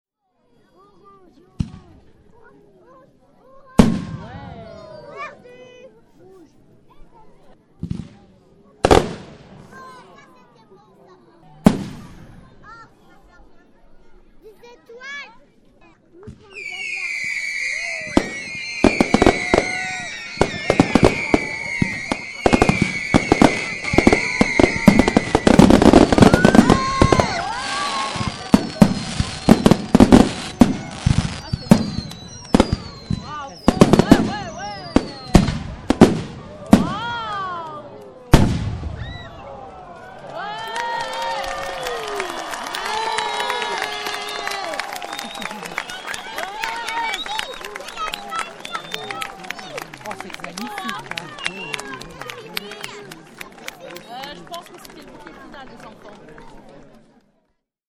La nuit venue, tout le monde se rassemble dans le pré, derrière l’église, et devant des yeux ébahis, c’est le ciel qui prend feu.
Le petit résumé de ce feu d’artifice, jusqu’au bouquet final, s’allume en cliquant sur le pétard. Vous pourrez y entendre les cris de joie des enfants et les commentaires des parents, entre deux fusées.
Enregistrements numériques réalisés le 14 juillet 2009 sur matériel ZOOM H4.